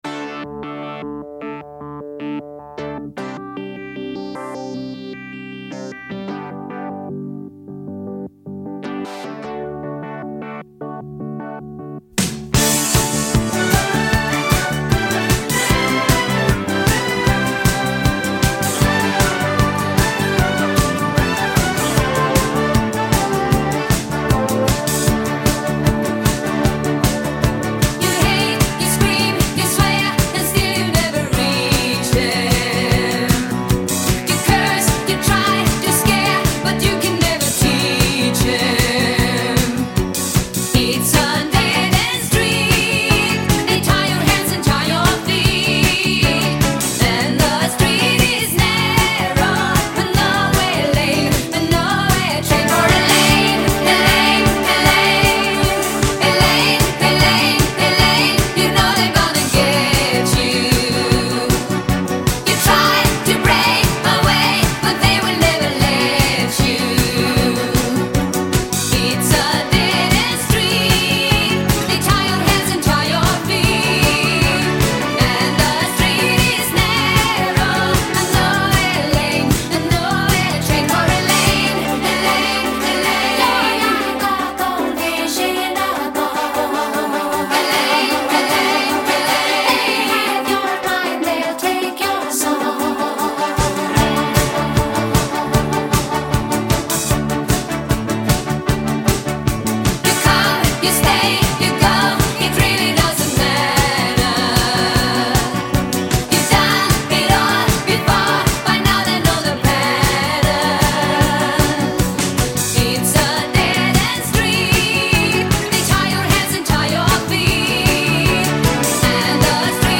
Europe • Genre: Pop